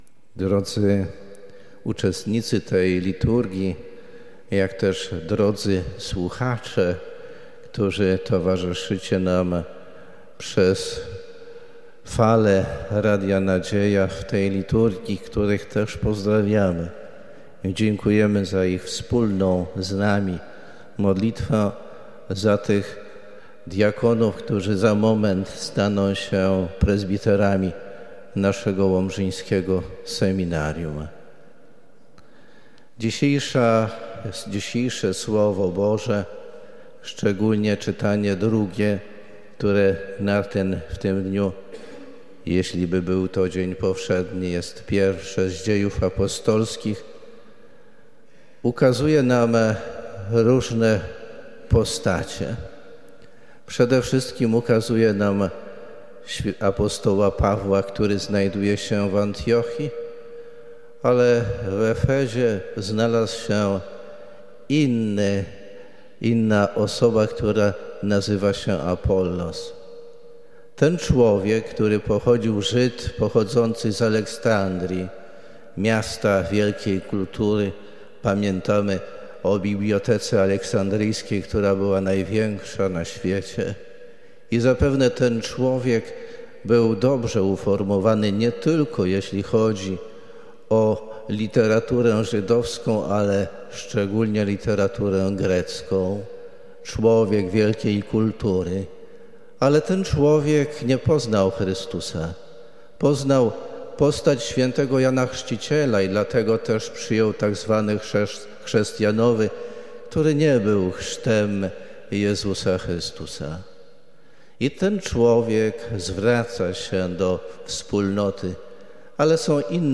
Uroczystej Mszy Świętej o g. 10.00 w Łomżyńskiej Katedrze przewodniczył ks. bp Janusz Stepnowski wraz z ks. bp. Tadeuszem Bronakowskim.
Homilia ks. bp. Janusza Stepnowskiego: